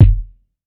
TC Kick 28.wav